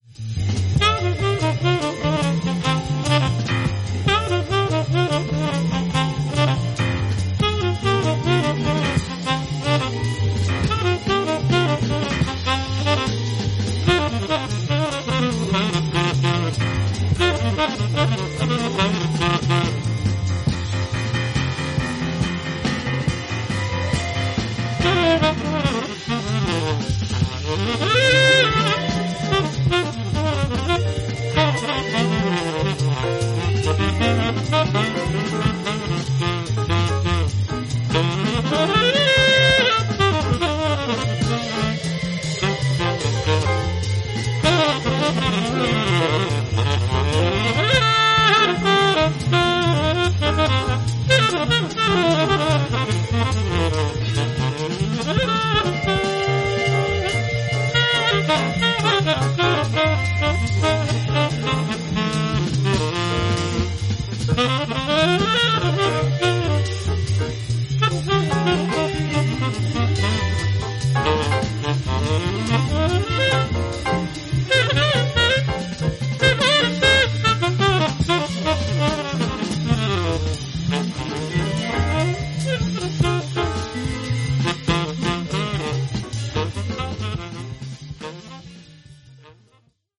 イタリアのライブラリーらしく、メロディが綺麗なボッサやソフトロックも良いですね。